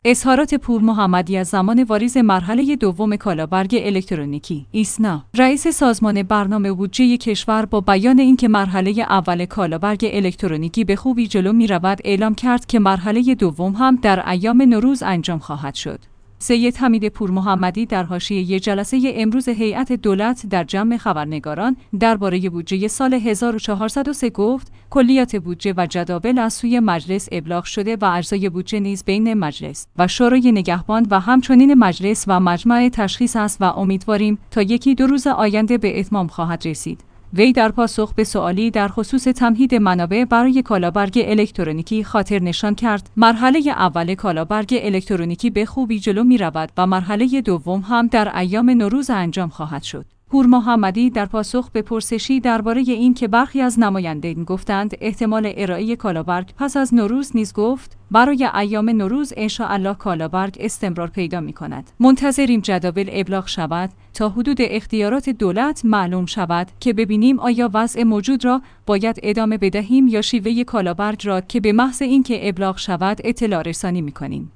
ایسنا/رئیس سازمان برنامه و بودجه کشور با بیان اینکه مرحله اول کالابرگ الکترونیکی به‌خوبی جلو می‌رود اعلام کرد که مرحله دوم هم در ایام نوروز انجام خواهد شد. سیدحمید پورمحمدی در حاشیه جلسه امروز هیئت دولت در جمع خبرنگاران درباره بودجه سال ۱۴۰۳ گفت: کلیات بودجه و جداول از سوی مجلس ابلاغ شده و اجزای بود